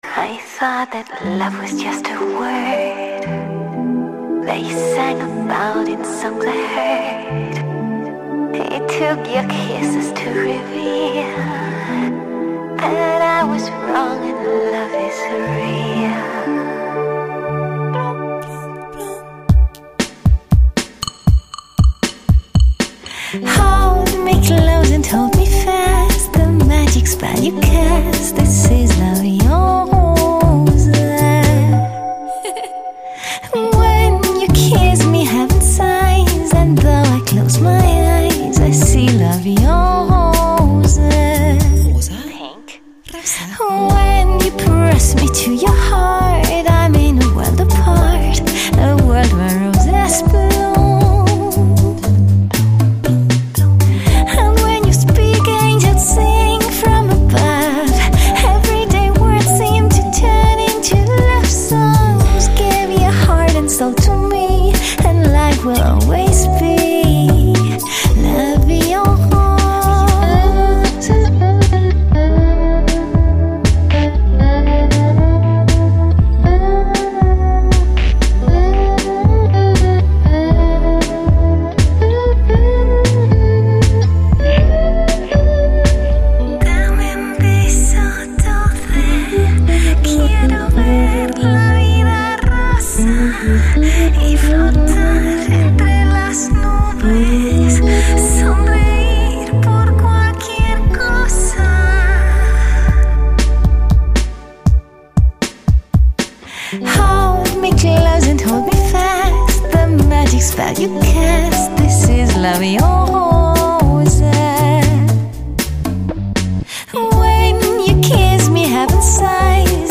Genre: Lounge | ChillOut | House | Lo-fi
Quality: MP3 | 320 kbps | 44,1Hz | Joint Stereo
compiles tracks imbued with sensuality